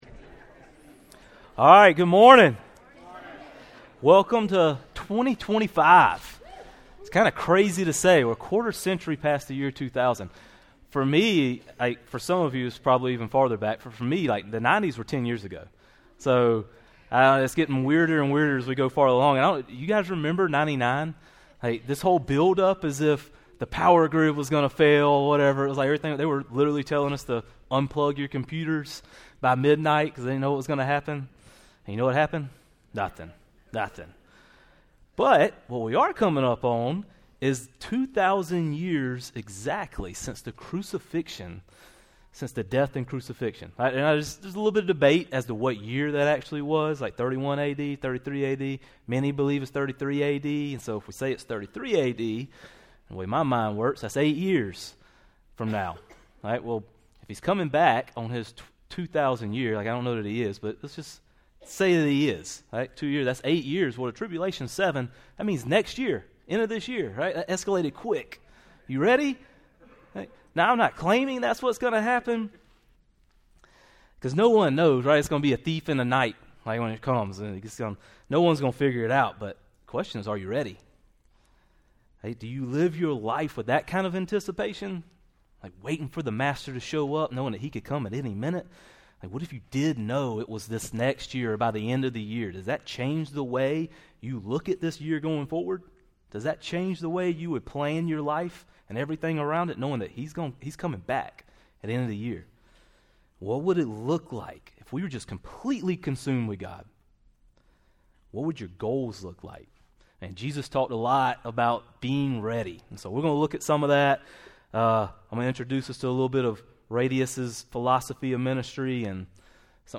Sermon Library | RADIUS Church